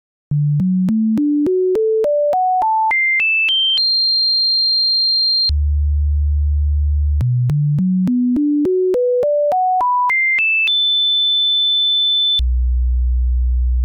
The largest change in pitch in the database, at 82,000 melodies, occurs in Ella Ketterer’s 1928 piano piece, Valse Petite, as it appears in Yours for a Song compiled by Janet E. Tobitt:
The MIDI drop in pitch is from the D two octaves above the treble staff down to the D below the bass clef staff.
If you play the audio recording of the piece, you may not hear either or both the highest and lowest notes, depending on the quality of your speaker hardware.